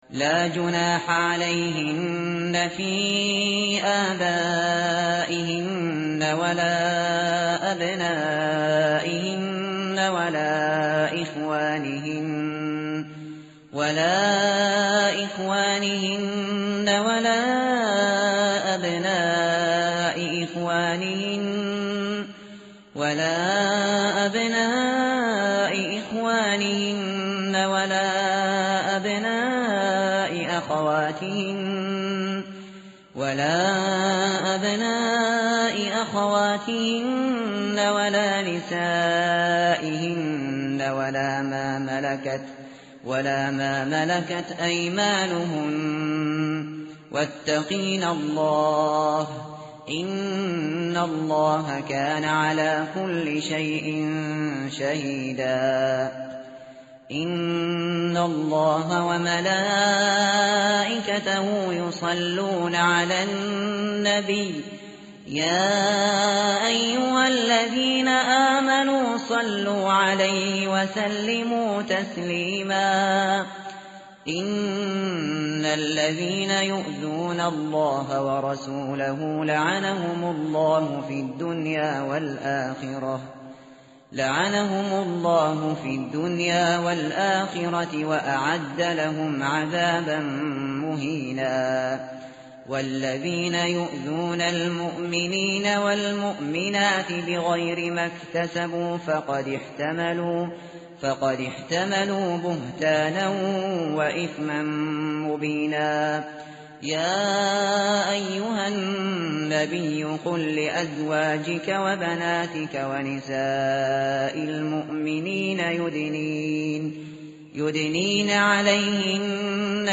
tartil_shateri_page_426.mp3